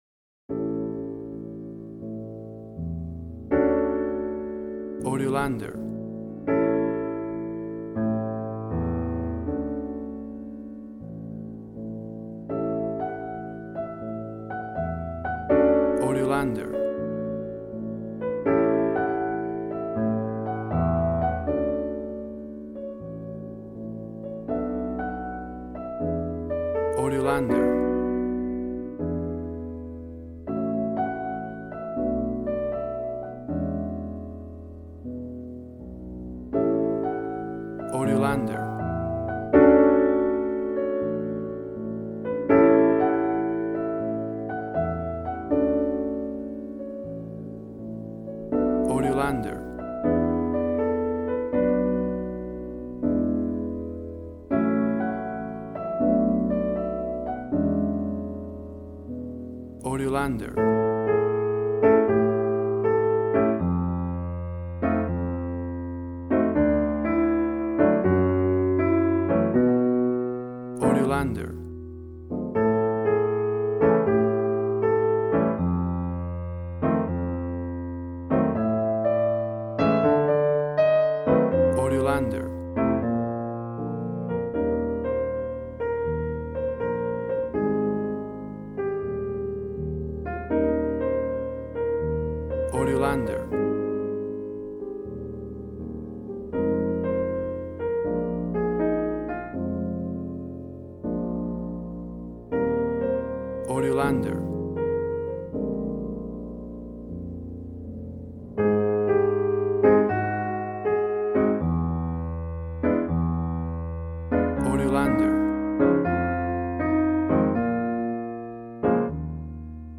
Smooth jazz piano.
Tempo (BPM): 80